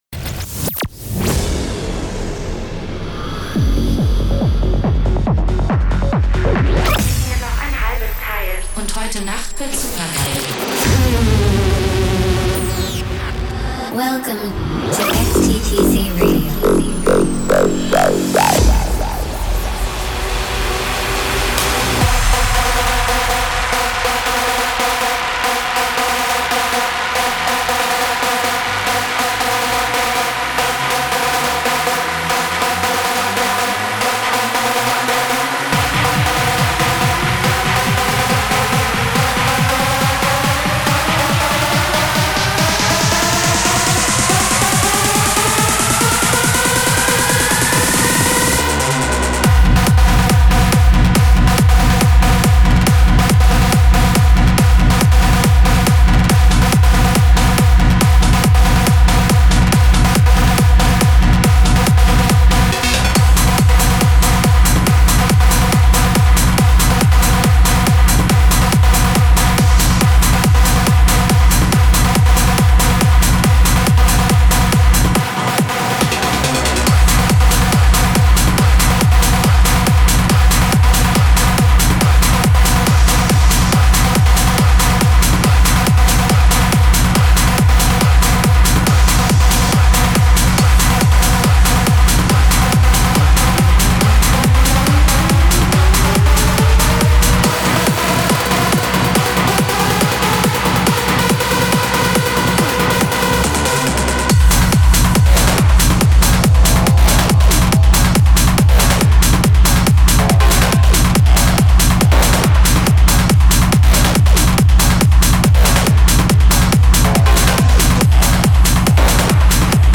music DJ Mix in MP3 format
Genre: Techno